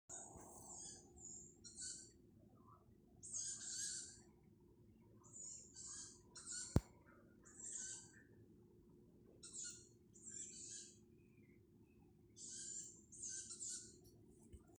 Meža pūce, Strix aluco
StatussDzied ligzdošanai piemērotā biotopā (D)
Piezīmes2 vai 3 jaunie pūcēni dzirdami katru vakaru piemājas lielajps kokos